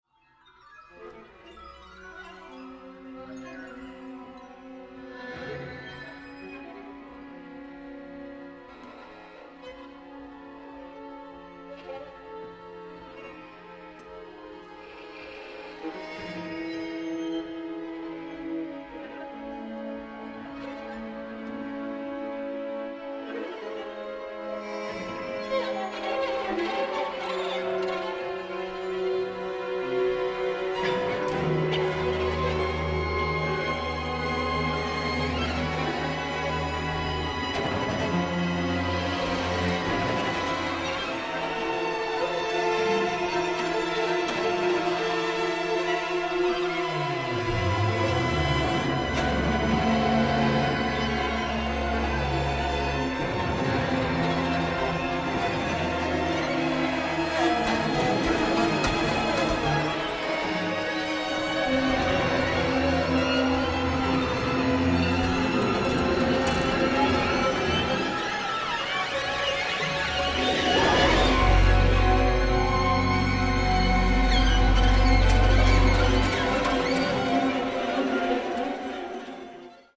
na orkiestrę smyczkową i elektronikę